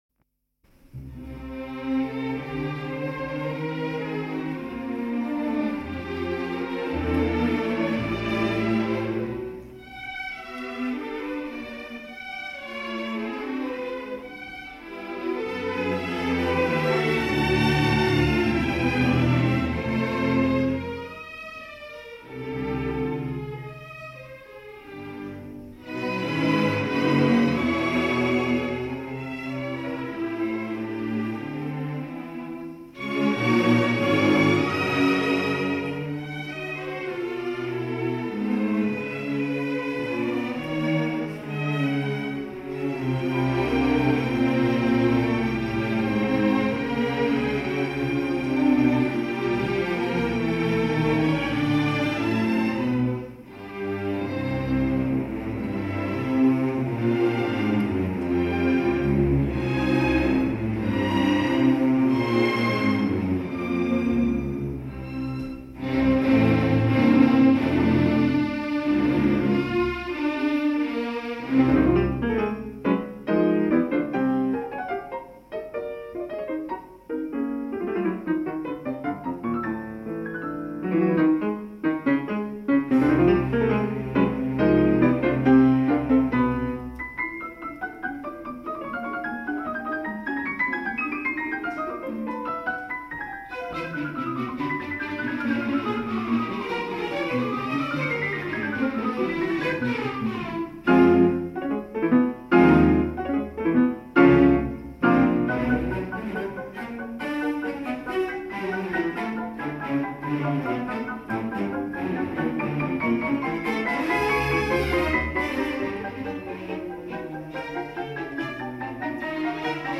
in a concert performance